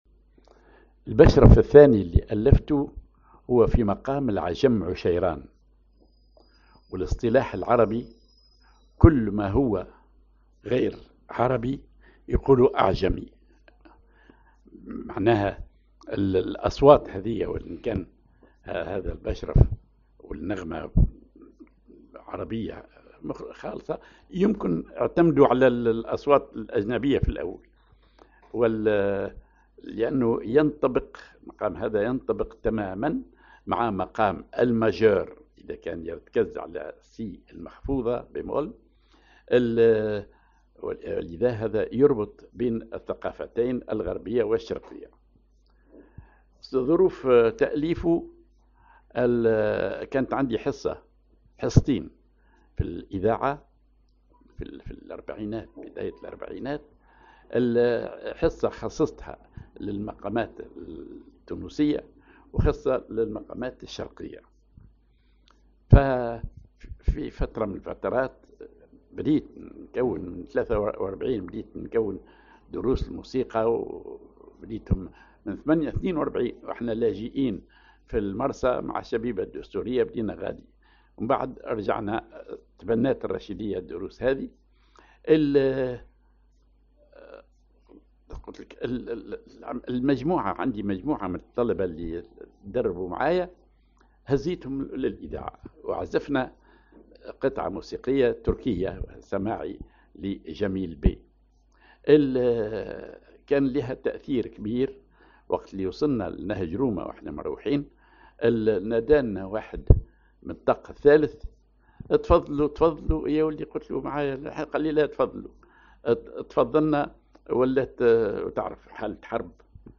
ar عجم عشيران